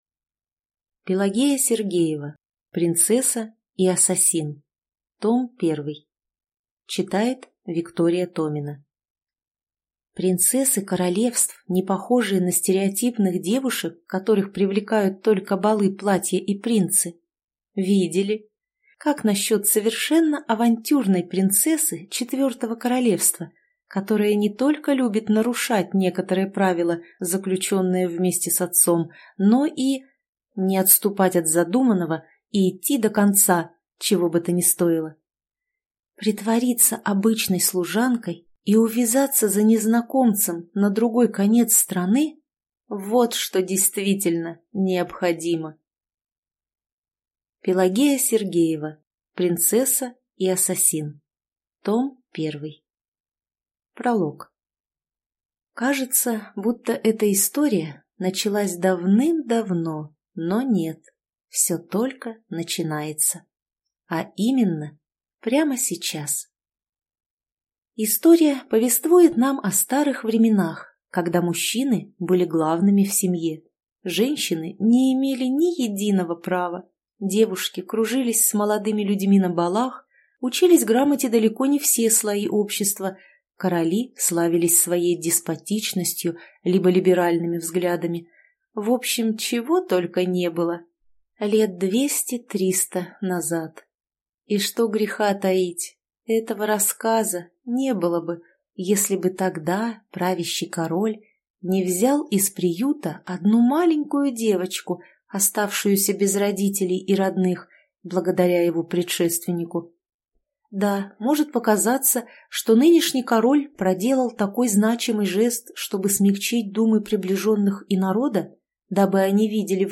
Аудиокнига Принцесса и ассасин. Том 1 | Библиотека аудиокниг